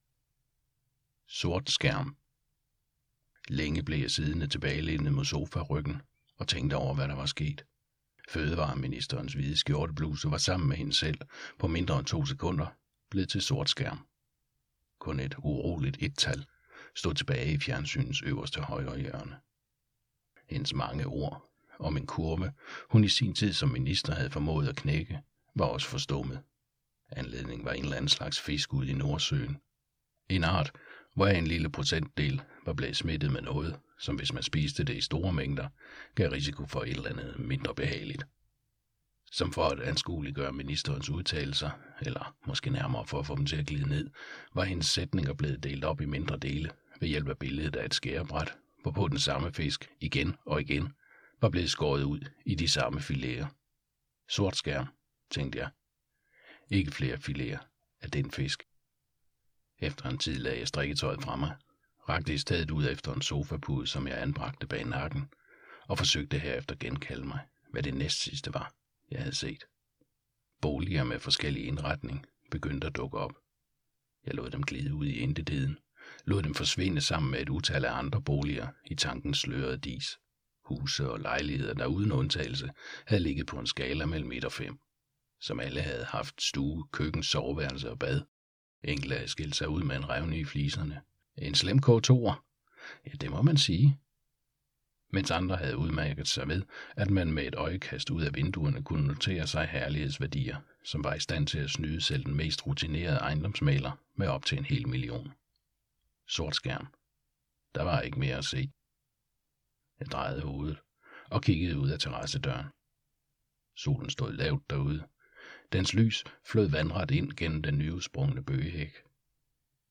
Hør et uddrag af Gribbe dræber ikke Gribbe dræber ikke Novellesamling Format MP3 Forfatter Bodil Sangill Lydbog E-bog 74,95 kr.